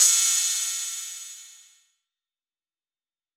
BWB THE WAVE CRASH (15).wav